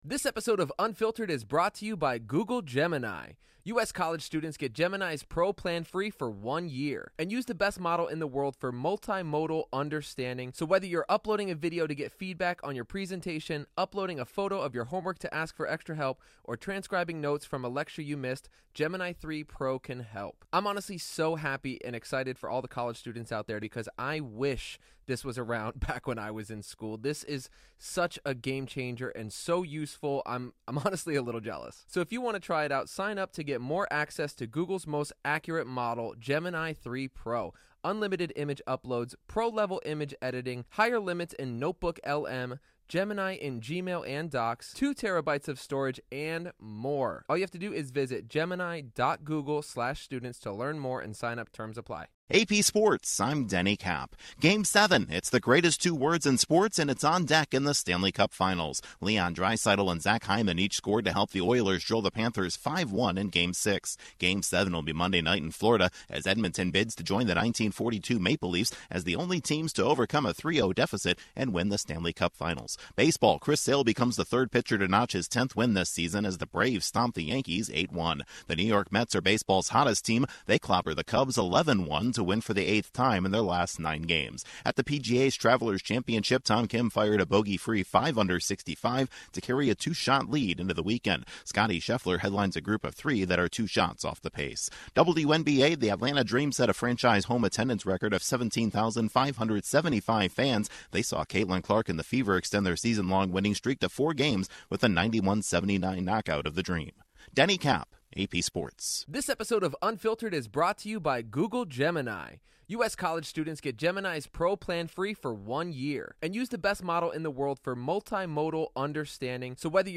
The Oilers force a Game 7, the Braves crush the Yankees, the Mets cruise, Tom Kim sets the pace and the Fever win again. Correspondent